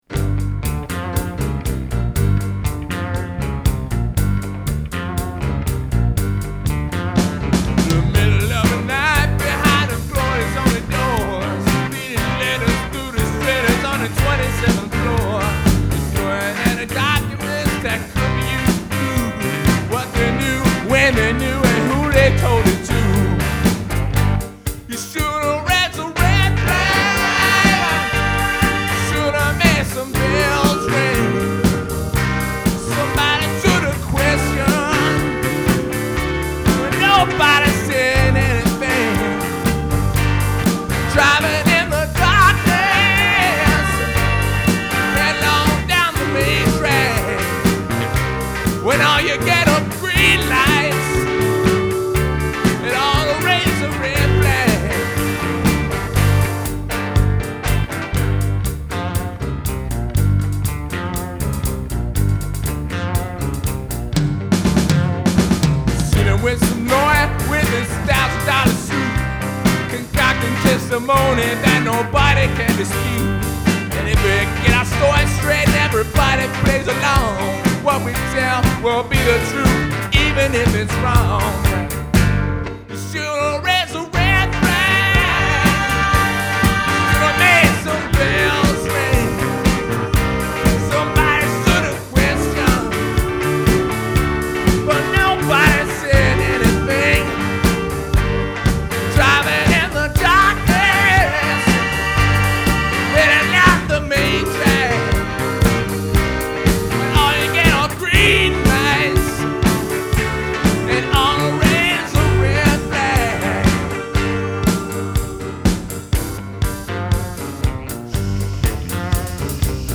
did a spot on Rolling Stones impression